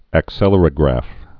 (ăk-sĕlər-ə-grăf)